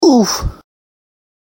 Add funny oof sound
oof.ogg